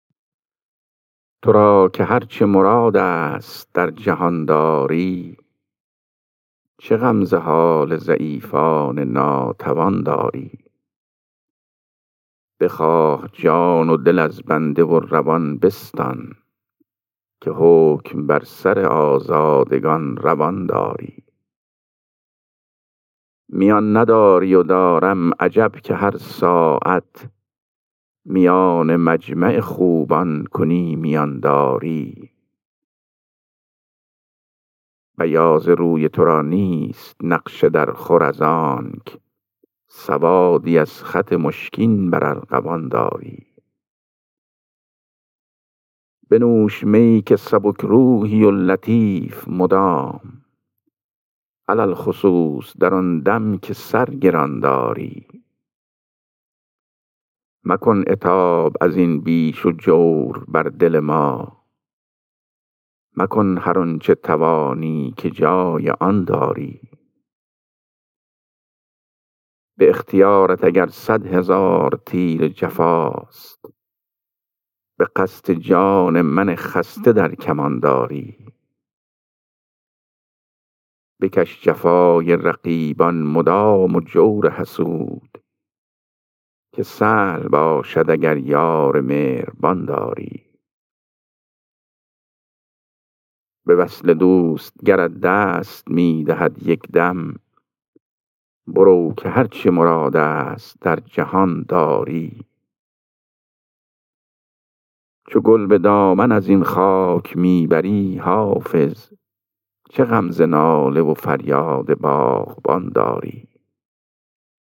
خوانش غزل شماره 445 دیوان حافظ